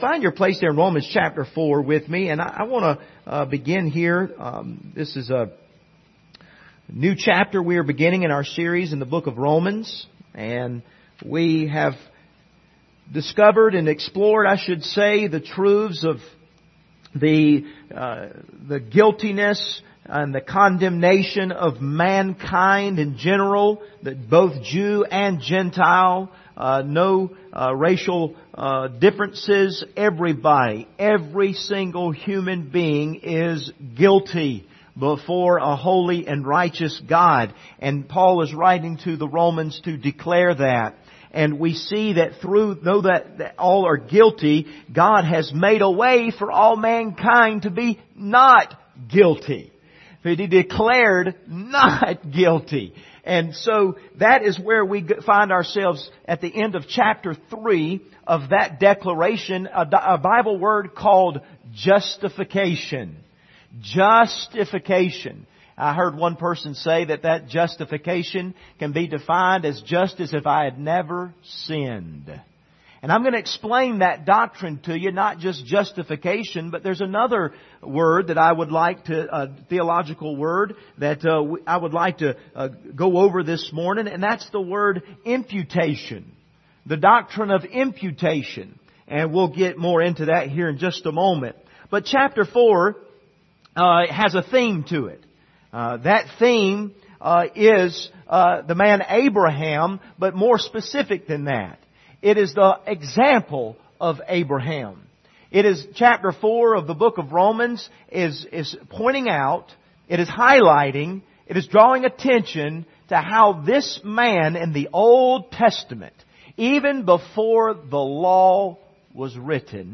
Passage: Romans 4:1-17 Service Type: Sunday Morning